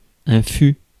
Ääntäminen
Synonyymit haste Ääntäminen France Tuntematon aksentti: IPA: /fy/ Haettu sana löytyi näillä lähdekielillä: ranska Käännöksiä ei löytynyt valitulle kohdekielelle.